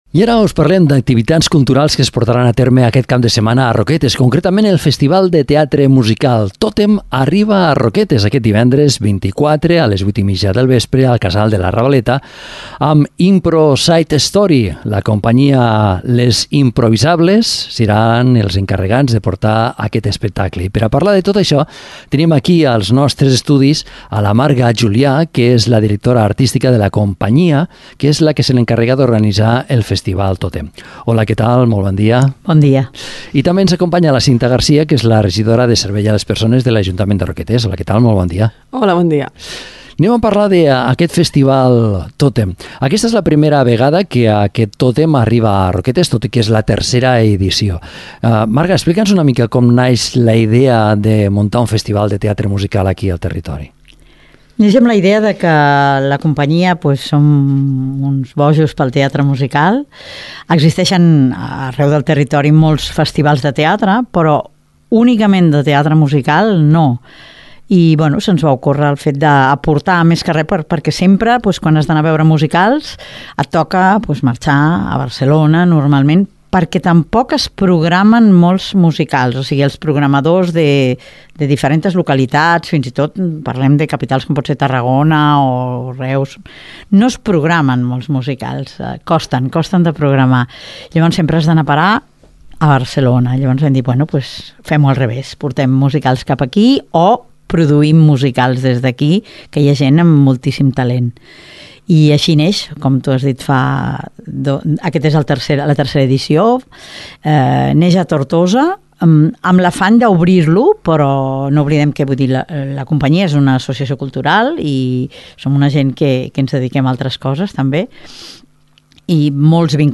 Entrevistem a, Cinta Garcia, regidora de Servei a les Persones de l’Ajuntament de Roquetes